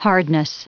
Prononciation du mot hardness en anglais (fichier audio)
Prononciation du mot : hardness